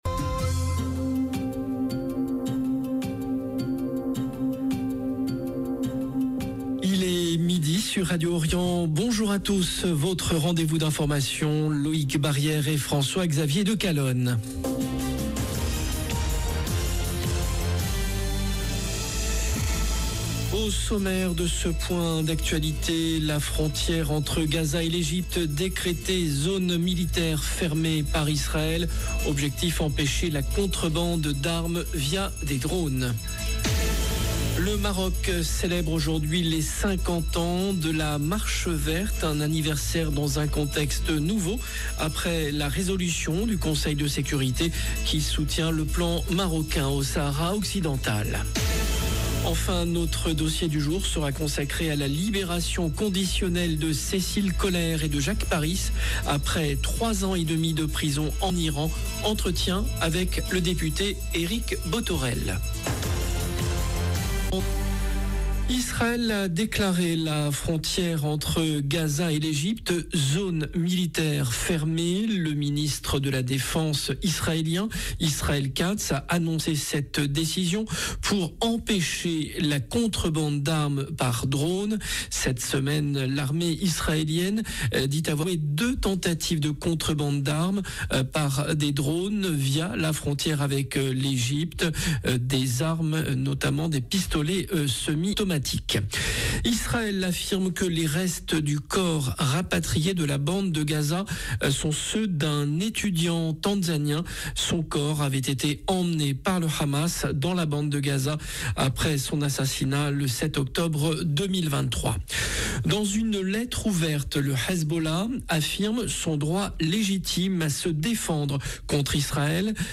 JOURNAL DE MIDI
Entretien avec le député Eric Bothorel 0:00 10 min 23 sec